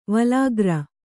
♪ valāgra